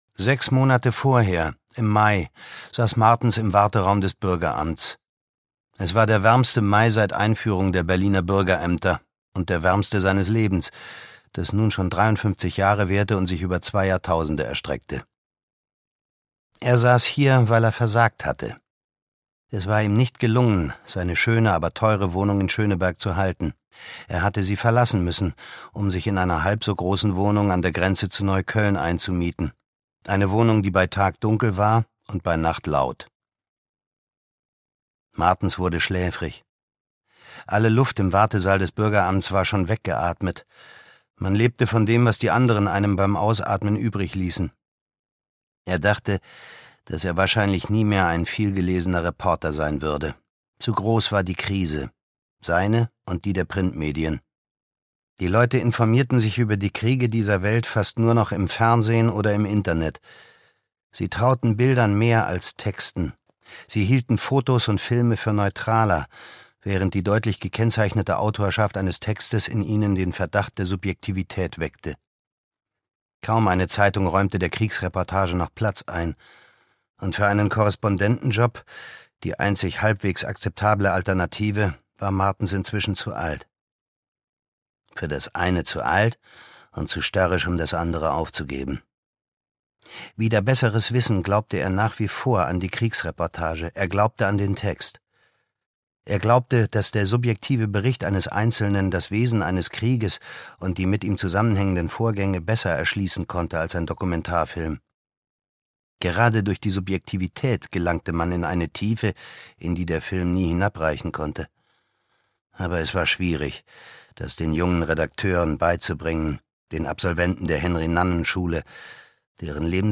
Das Leuchten in der Ferne - Linus Reichlin - Hörbuch